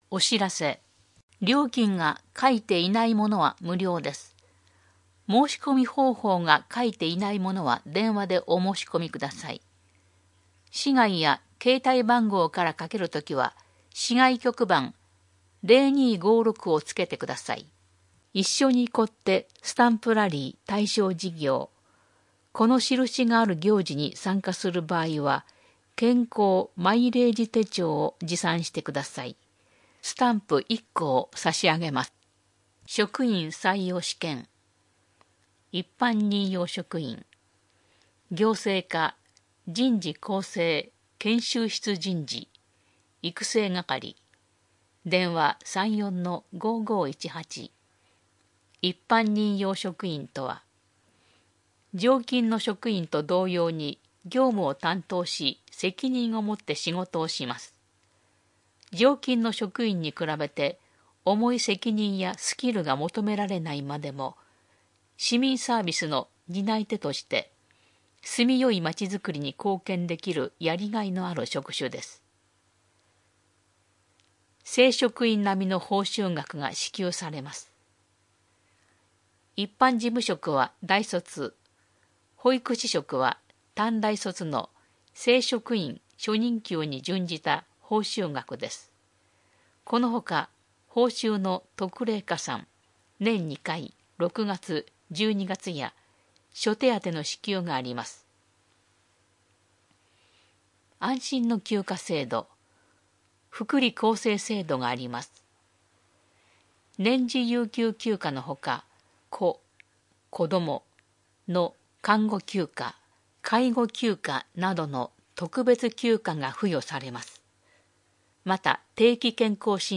広報さんじょうを音声でお届けします。